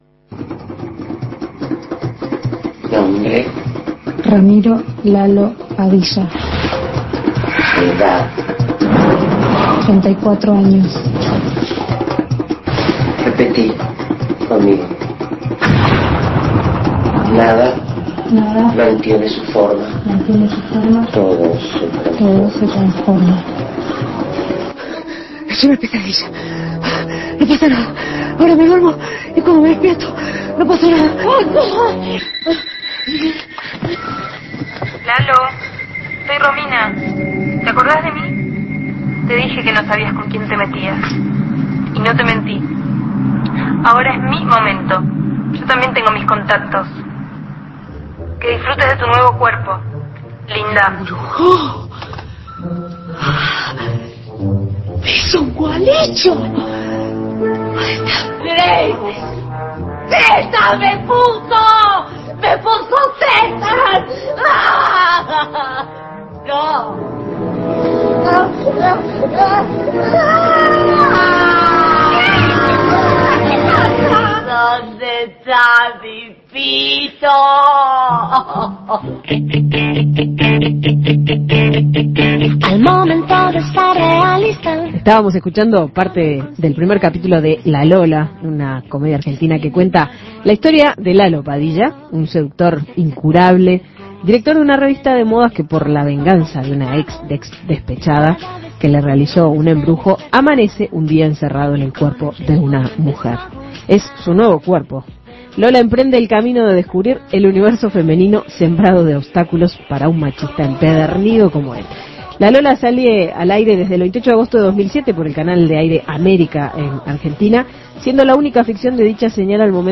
Escuche la entrevista con Carla Peterson